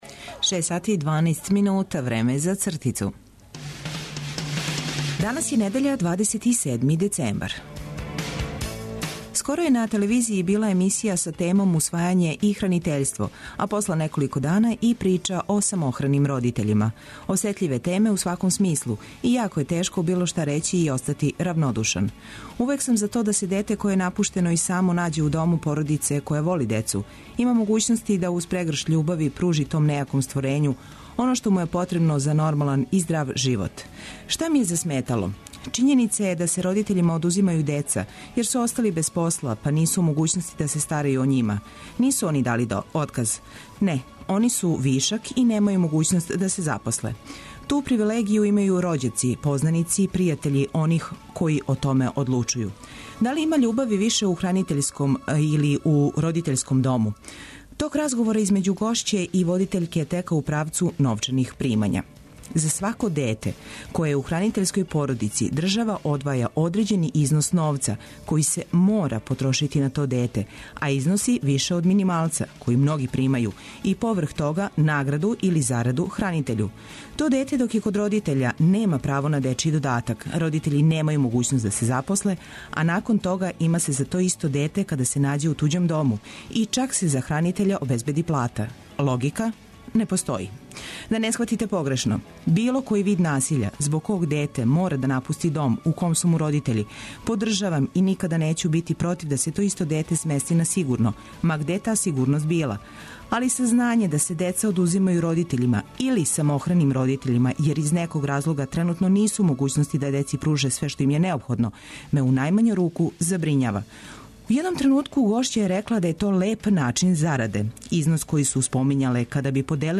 Добра музика, сервисне, културне и спортске информације су само део УРАНКА 202 и овога јутра.